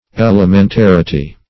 Elementarity \El`e*men*tar"i*ty\, n.
elementarity.mp3